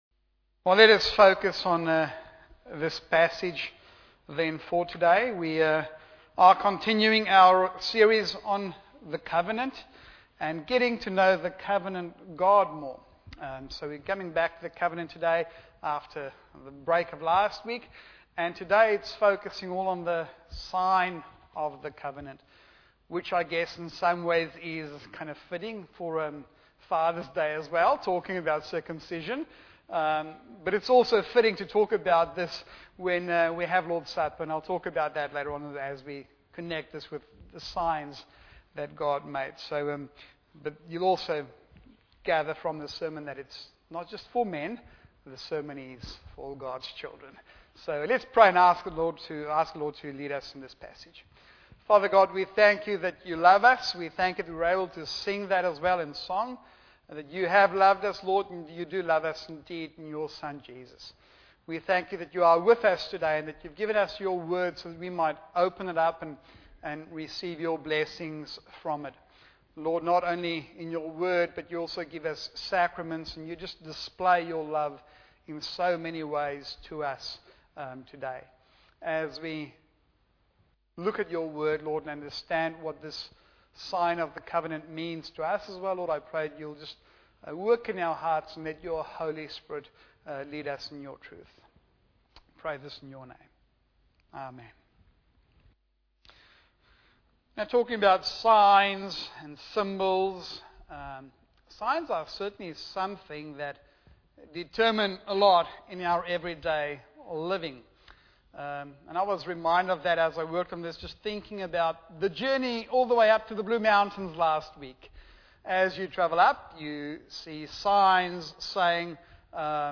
Bible Text: Genesis 17:1-16 | Preacher